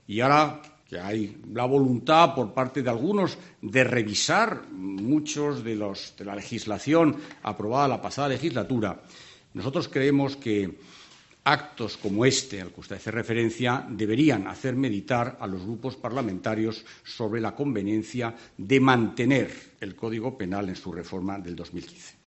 Íñigo Méndez de Vigo, portavoz del Gobierno y ministro de Educación, Cultura y Deporte